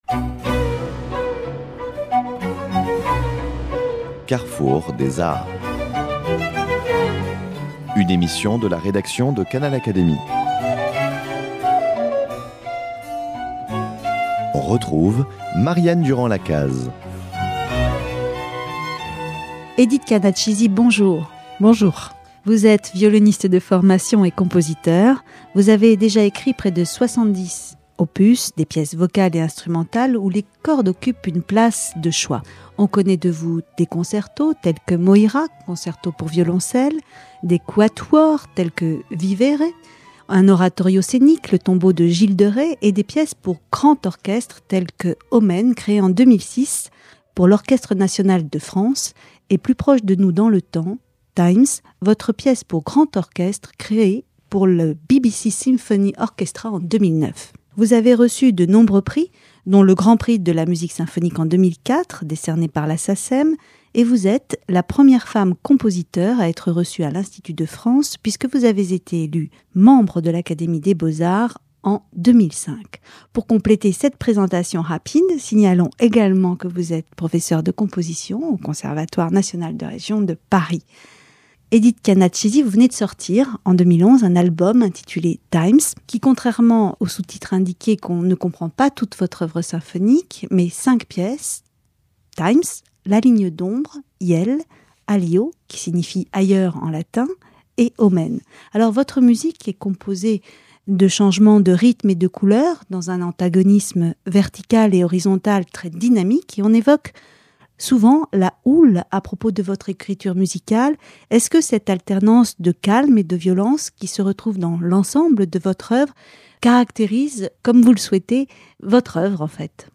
Première femme compositeur élue à l’Académie des beaux-arts, Edith Canat de Chizy revient dans cette interview sur vingt ans d’écriture pour orchestre, à l’occasion de la sortie de son album, Times.